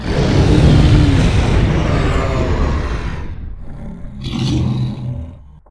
combat / creatures / dragon / he / die1.ogg
die1.ogg